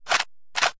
snd_akreload.ogg